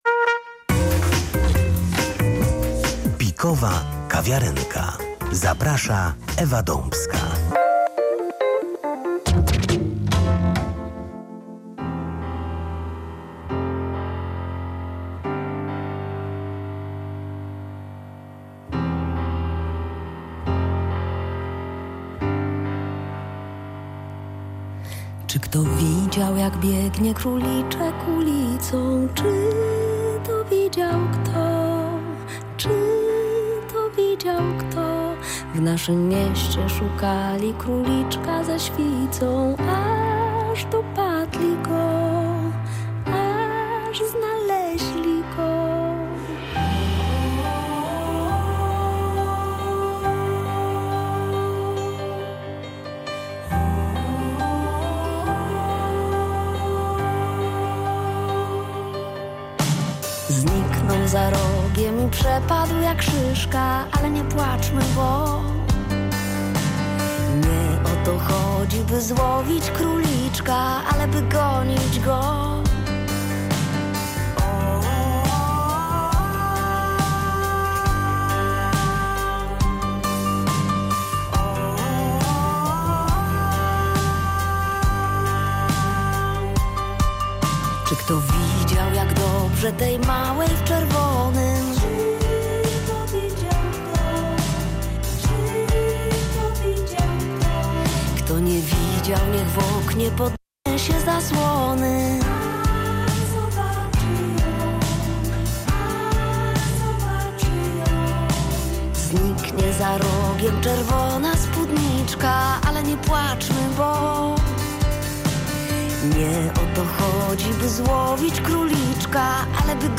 Zapis rozmowy na kanale YouTube UKW: